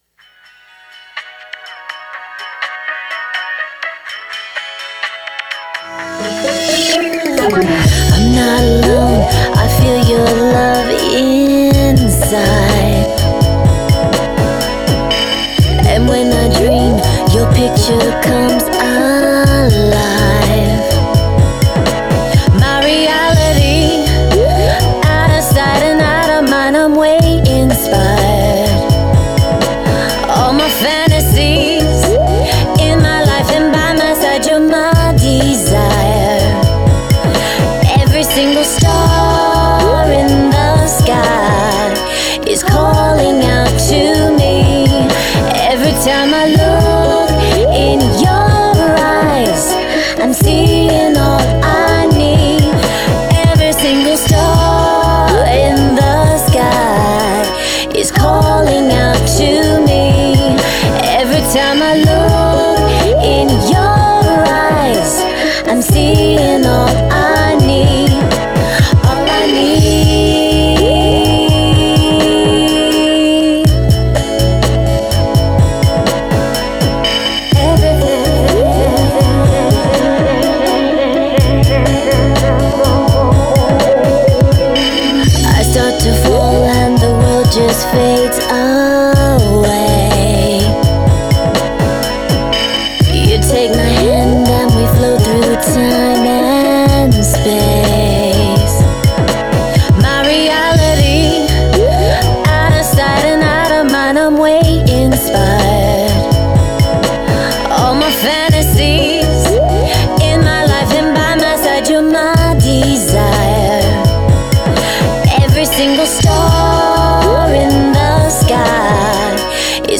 Interviewed
at the L.A. Film School